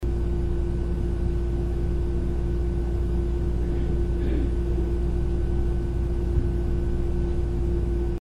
This will sound like white noise in the background.
The first is unenhanced but is very hard to to hear the male voice that sounds like someone clearing his throat.